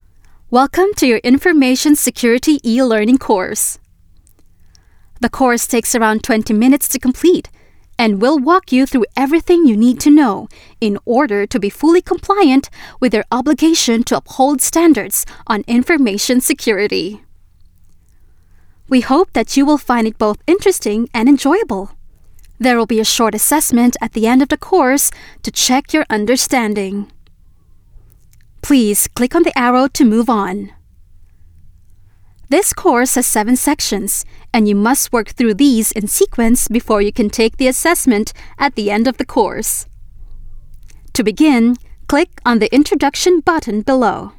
PH ENGLISH FEMALE VOICES